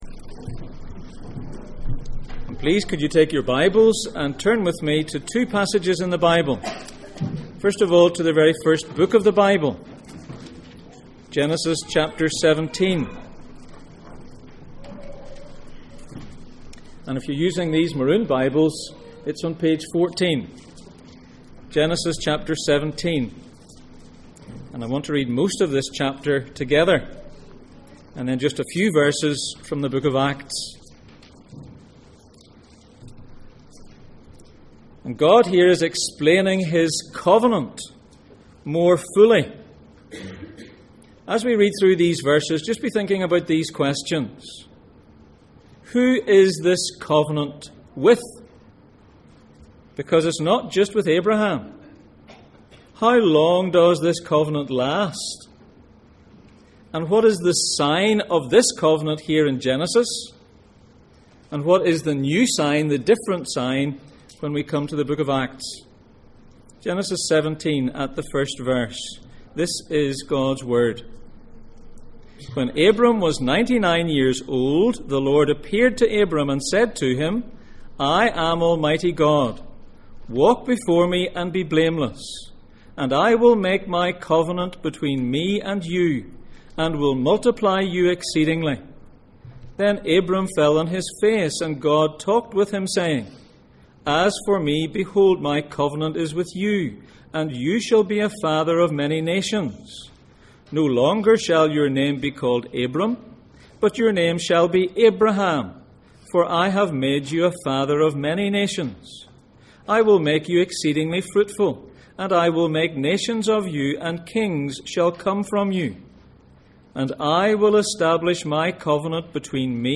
Baptism Passage: Genesis 17:1-27, Acts 2:36-39 Service Type: Sunday Morning %todo_render% « The shepherd feeds the sheep Can a piece of bread give you eternal life?